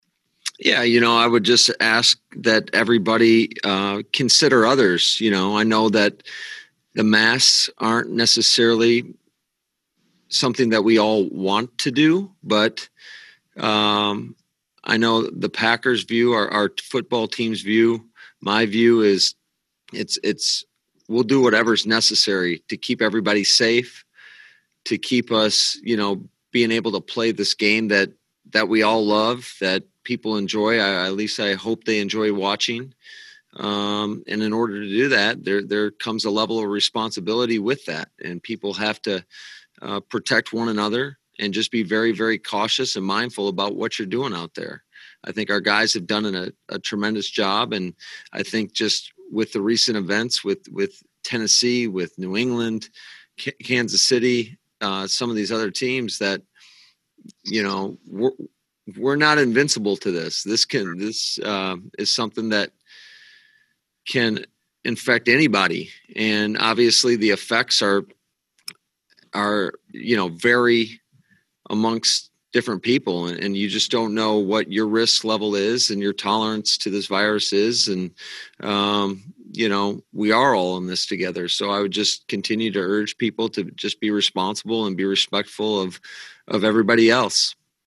Matt LaFleur zooms with the media on Saturday.
Head Coach Matt LaFleur was asked if there’s anything he can say about the deteriorating state of public health and how he’ll share that advice with his team: